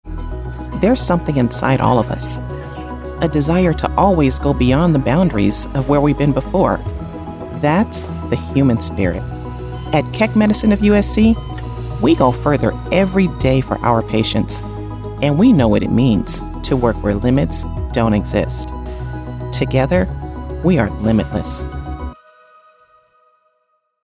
Female
My voice is authentic, smooth and can be raspy at times. It is professional, articulate, trustworthy, assertive, warm, inviting and believable. It's also quite soothing.
Television Spots